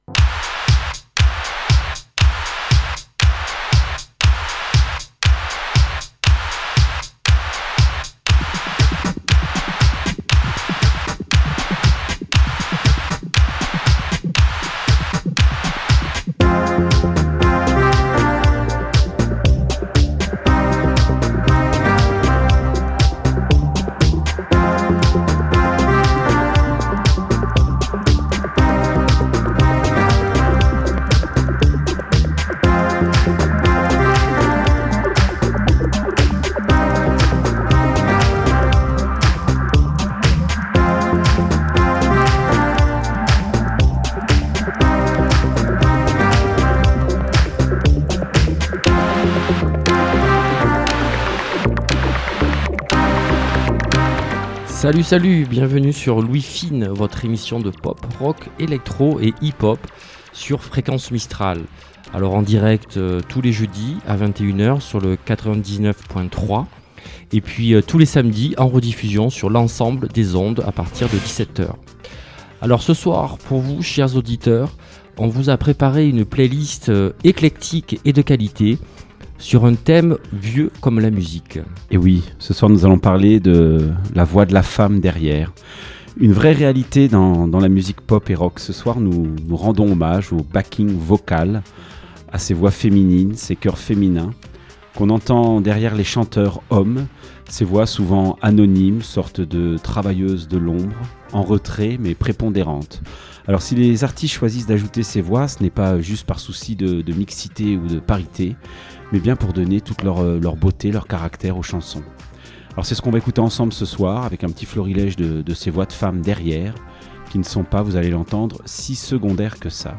La fièvre rock s’empare des ondes de Fréquence Mistral !
De Manchester à New York, de Paris à Détroit, venez écouter les nouveautés et tous les artistes qui font l’histoire de la pop, du rock et de l’électro.